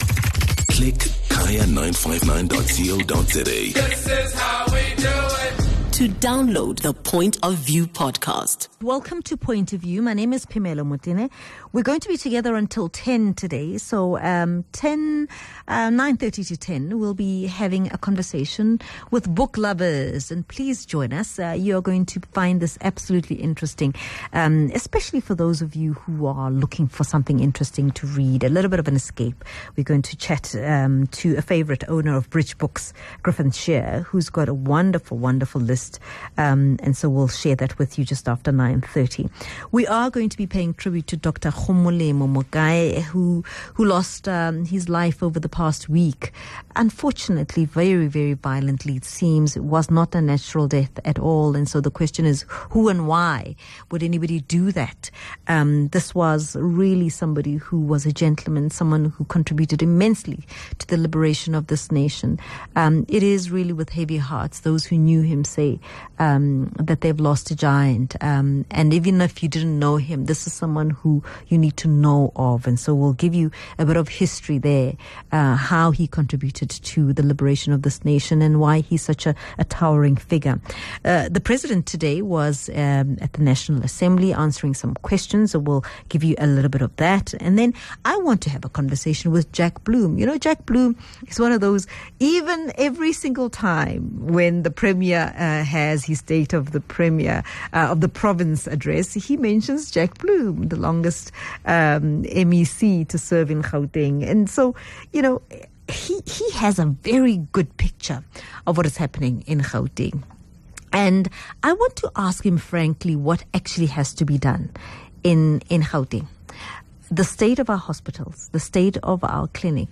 speaks to MMC for Roads and Transport in the City of Ekurhuleni, Andile Mngwevu